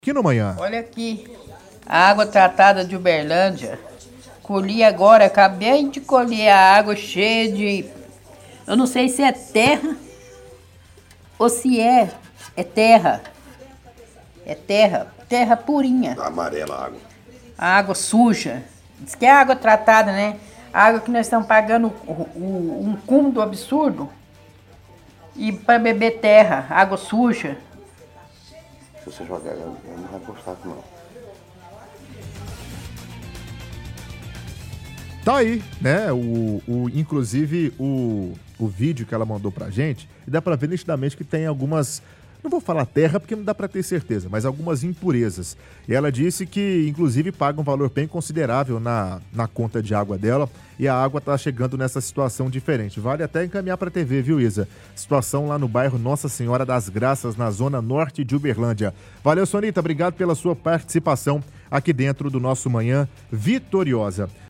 – Ouvinte reclama de água com muitas impurezas no bairro Nossa Senhora das Graças.